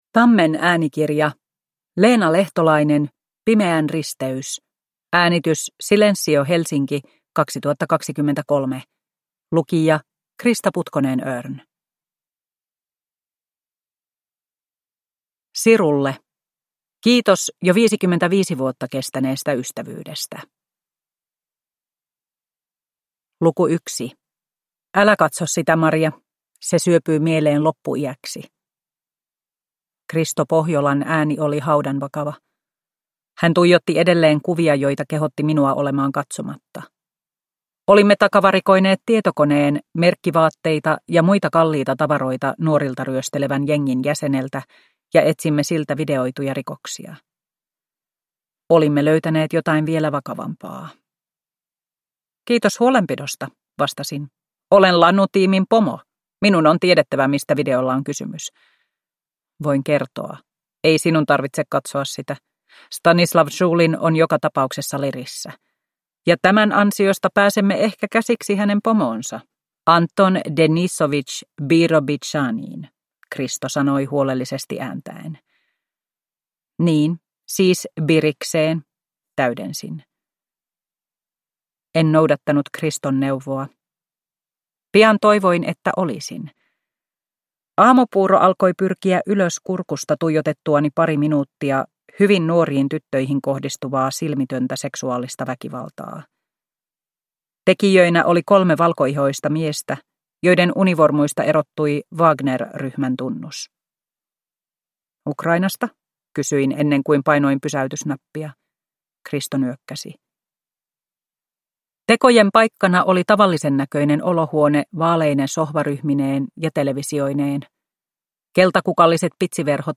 Pimeän risteys – Ljudbok – Laddas ner